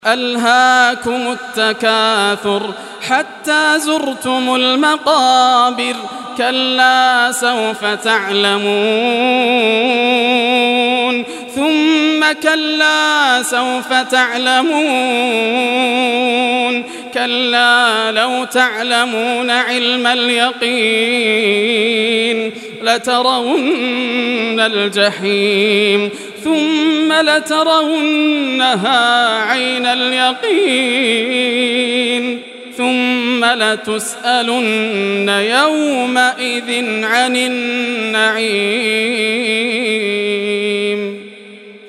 Surah Takasur Recitation by Yasser al Dosari
Surah Takasur, listen or play online mp3 tilawat / recitation in Arabic in the beautiful voice of Sheikh Yasser al Dosari.
102-surah-takasur.mp3